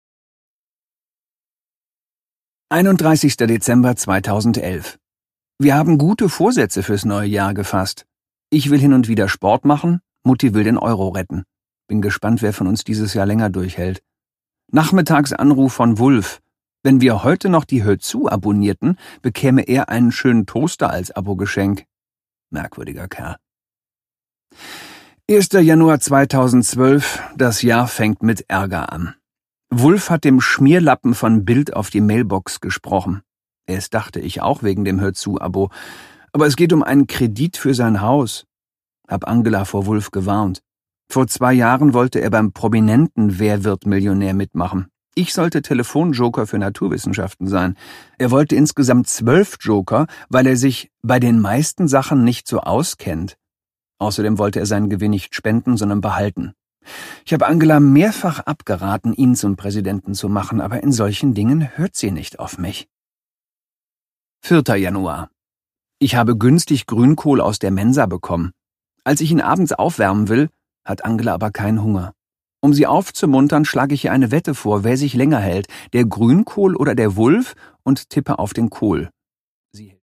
Produkttyp: Hörbuch-Download
Fassung: Autorisierte Lesefassung
Gelesen von: Christoph Maria Herbst